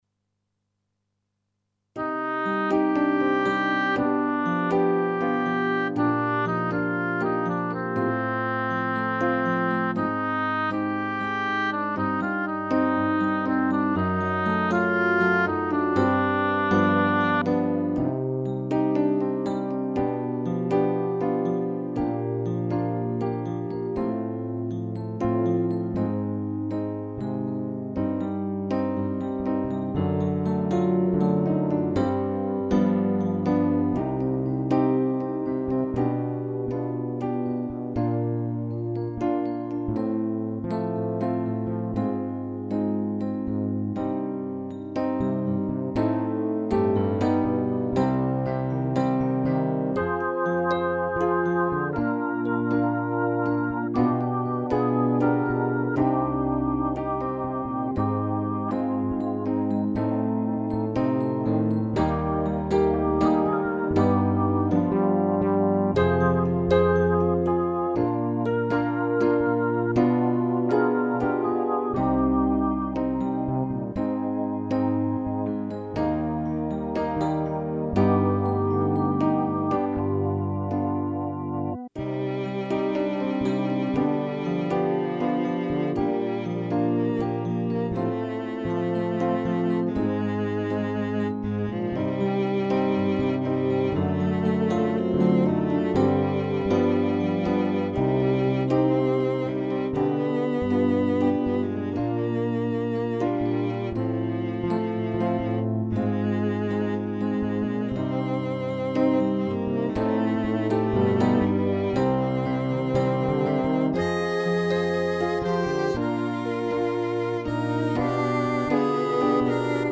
pour choeur de femmes à trois voix
accompagnement play-back
avec 8 mesures d'intro